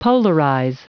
Prononciation du mot polarize en anglais (fichier audio)
Prononciation du mot : polarize
polarize.wav